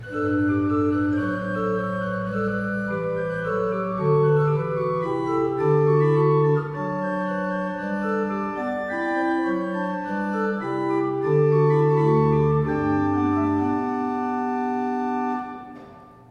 kostel sv. Jana Nepomuckého
Nahrávky varhan:
Vsemina, Kopula major, Kvinta 3.mp3